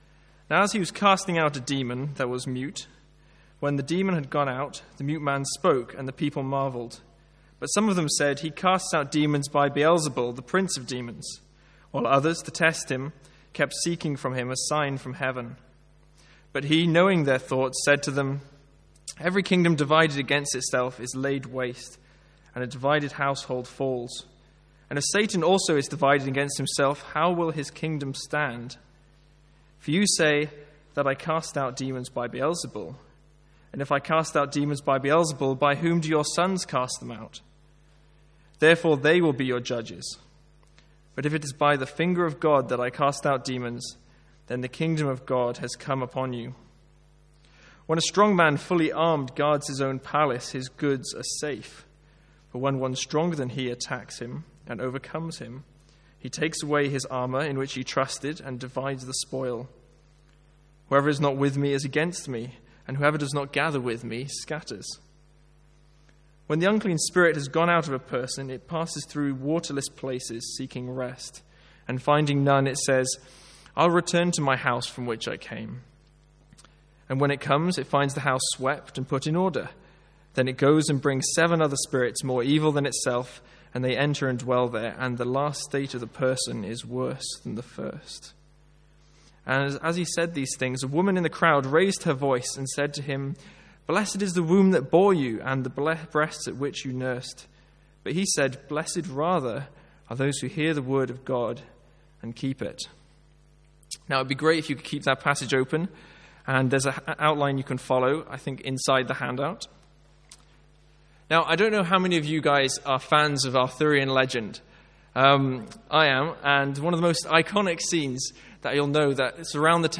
Sermons | St Andrews Free Church
From the evening series in Luke.